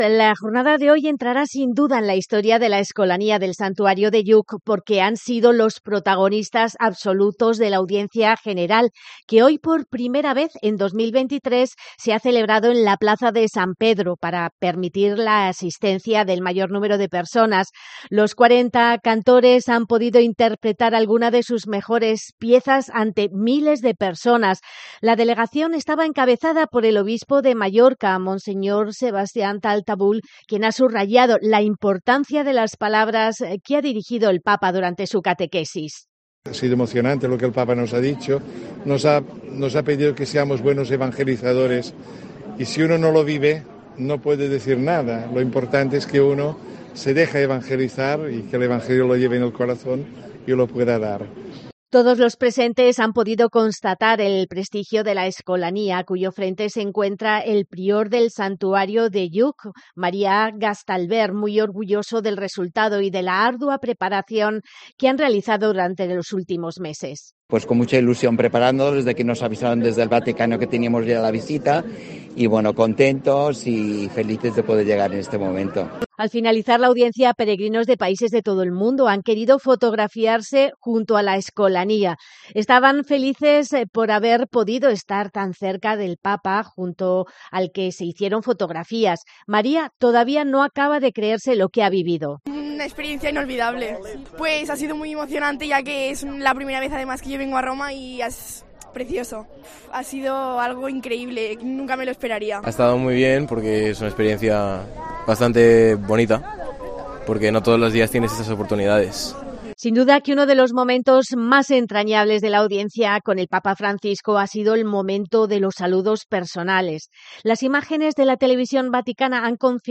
Los 40 niños y niñas que integran la Escolanía han ofrecido un concierto de dos Salves durante la audiencia.